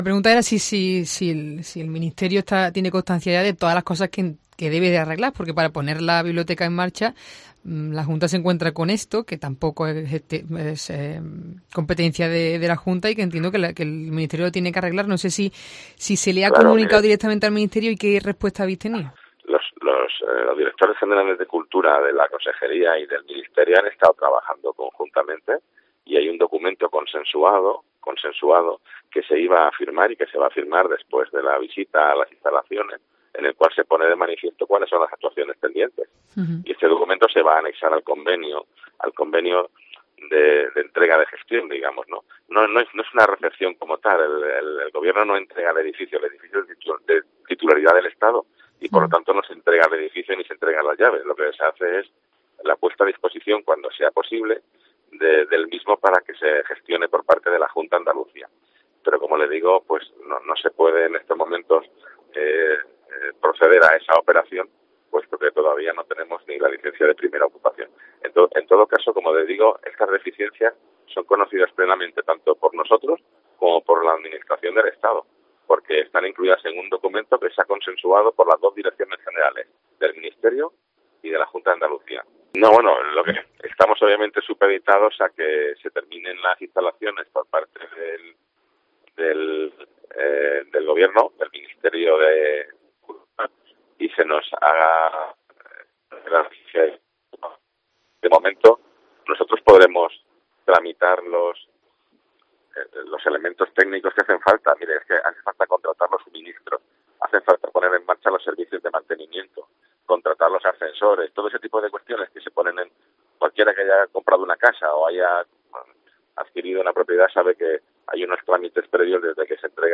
De este modo, se transfiere la gestión al Gobierno autonómico, que estará representado en el acto por su consejero de Turismo, Cultura y Deporte, Arturo Bernal.